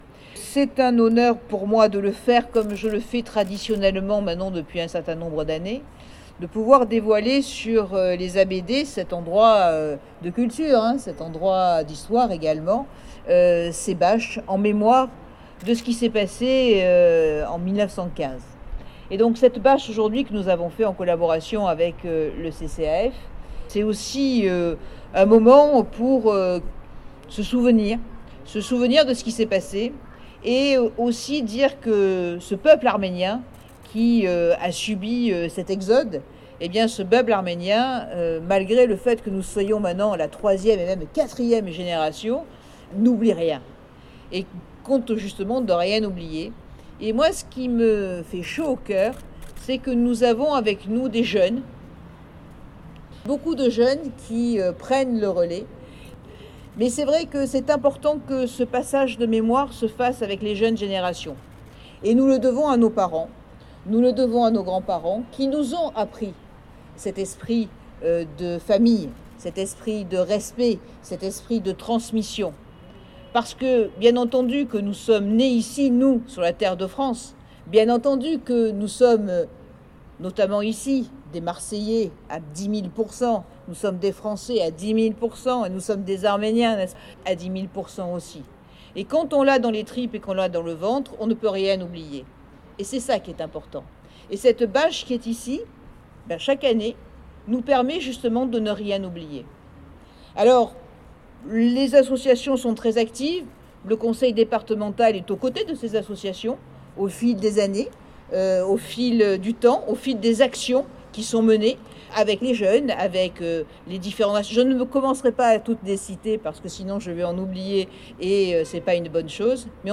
C’est Martine Vassal qui ouvrira la cérémonie devant un public masqué, volontairement réduit et tenu à distance par des marques au sol, devant une reproduction de la bâche, qui était déjà en place sur la façade des ABD.
Intervention de Martine Vassal